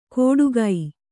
♪ kōḍu